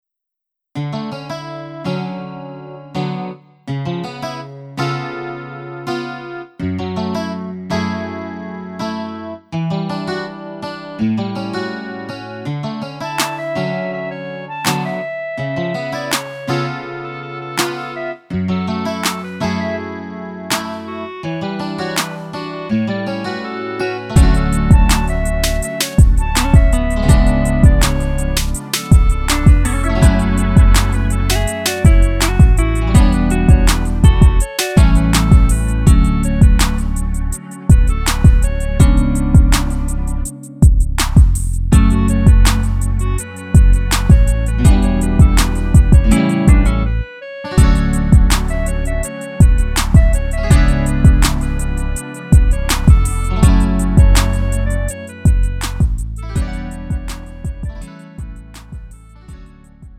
음정 -1키 3:23
장르 가요 구분